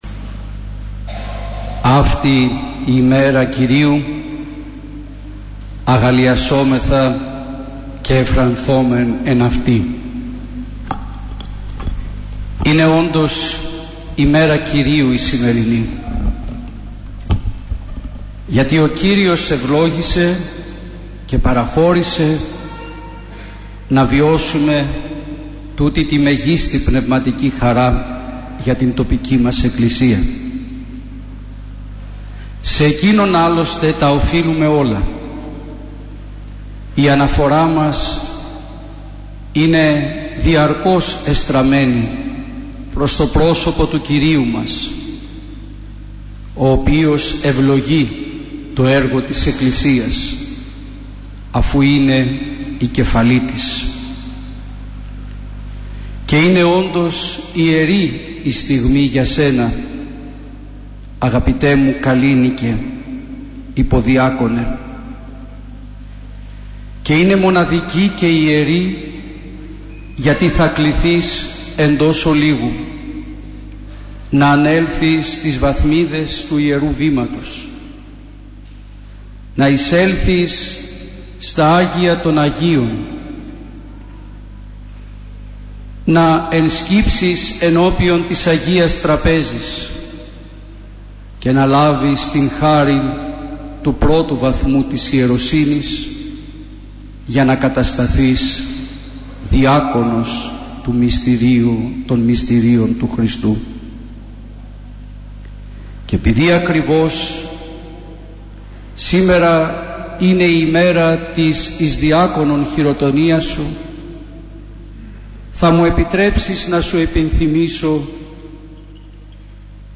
Χειροτονία Διακόνου στην Μητρόπολη Αιτωλίας και Ακαρνανίας